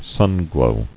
[sun·glow]